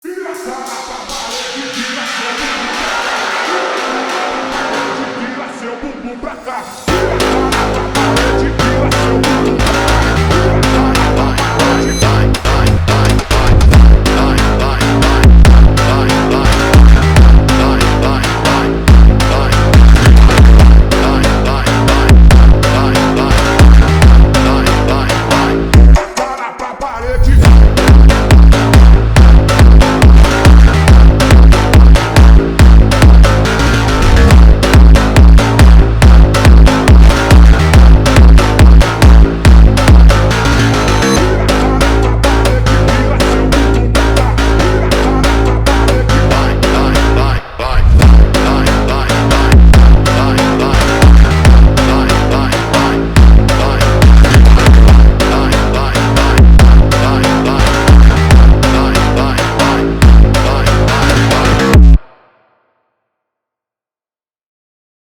Скачать музыку / Музон / Фонк (Phonk)